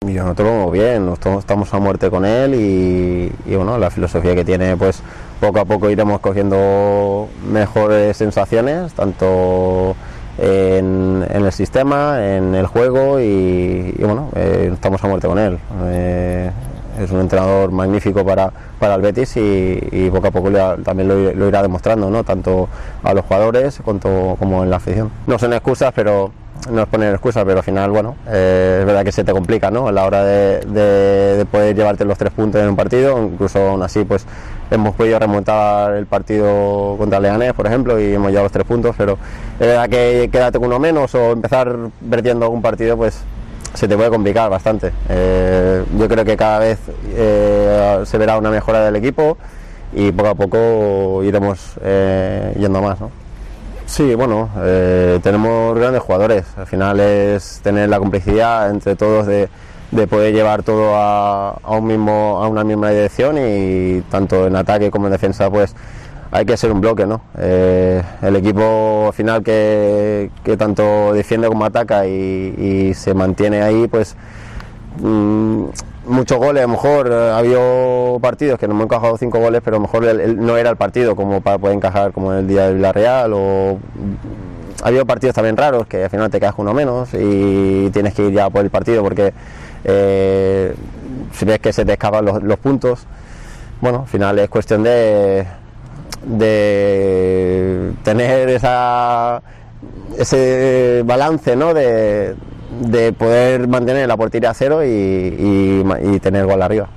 El lateral ha mostrado su apoyo al técnico en una entrevista a Eldesmarque